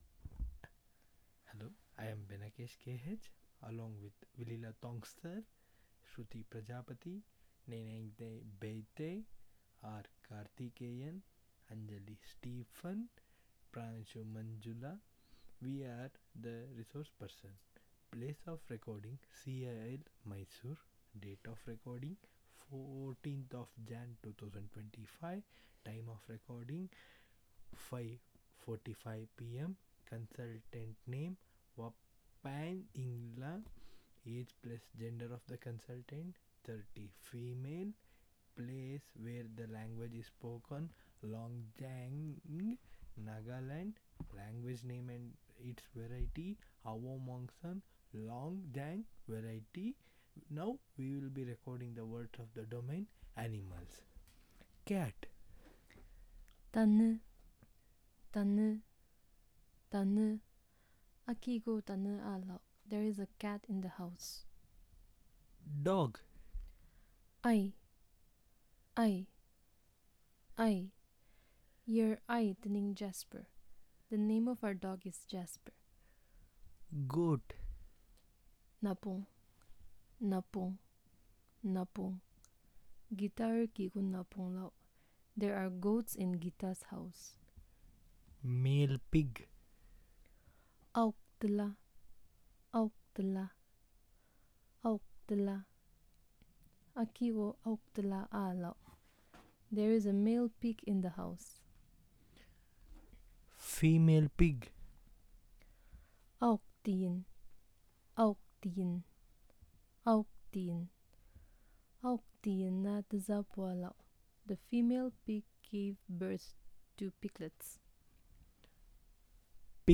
Elicitation of sentences on the domain of Animals